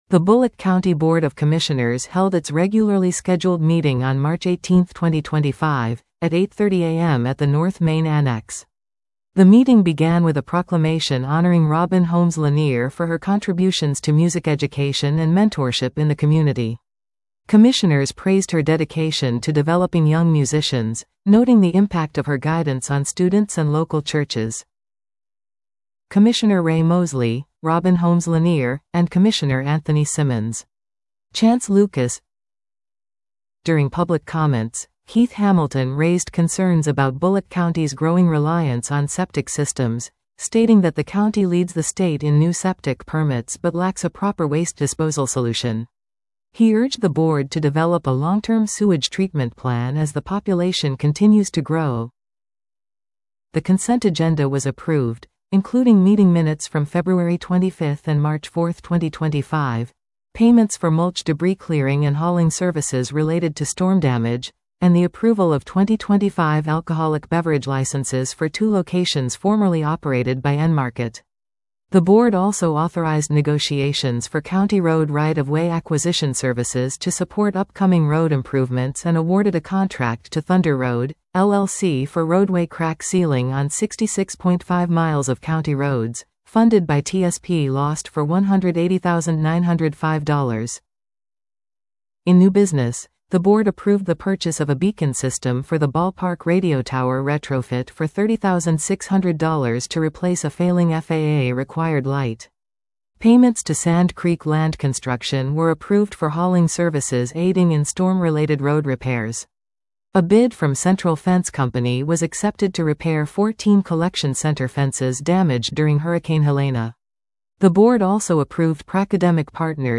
The Bulloch County Board of Commissioners held its regularly scheduled meeting on March 18, 2025, at 8:30 AM at the North Main Annex.